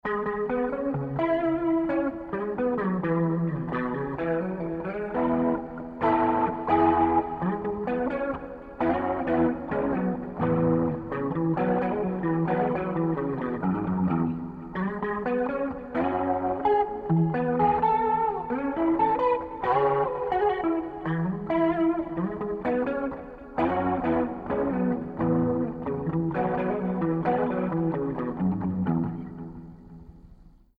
Para exemplificar essa história, mostraremos o processo de dois guitarristas que enviaram suas gravações realizadas em casa para nosso estúdio online.
Caixa Leslie